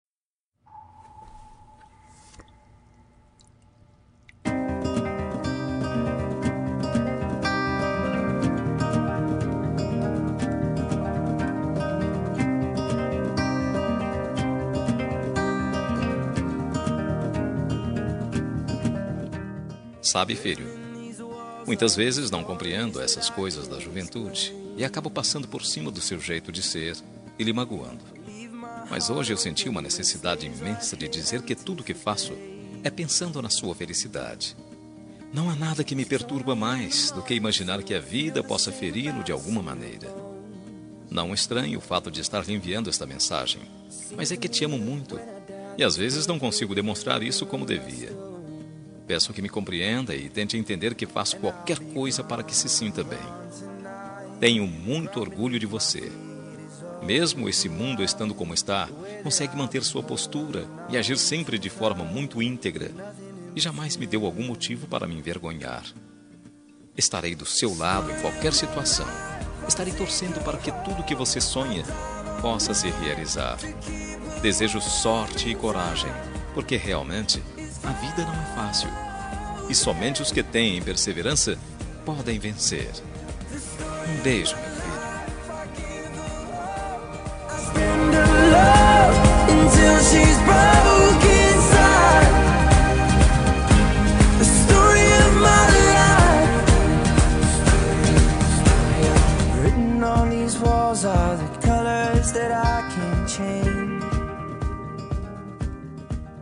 Homenagem para Filho – Voz Masculino – Cód: 8132